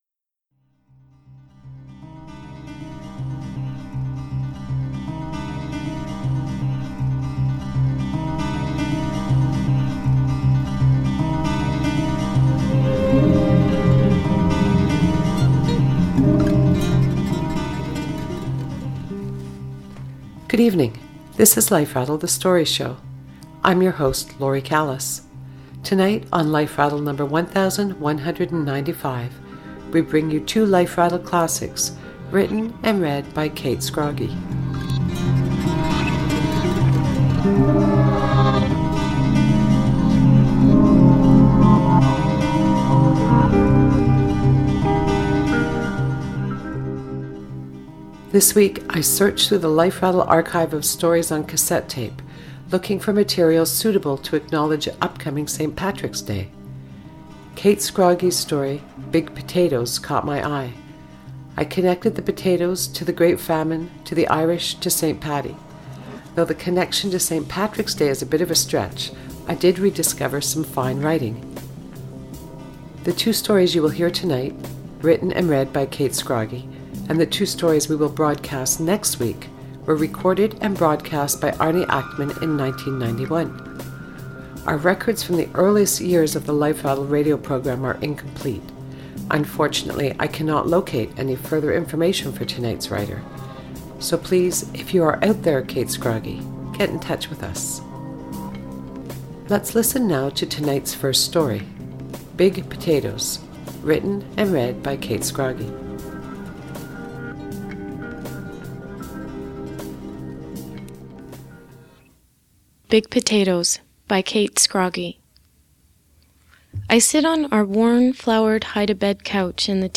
This week I searched through the Life Rattle archive of stories on cassette tape, looking for material suitable to acknowledge upcoming St. Patrick’s Day.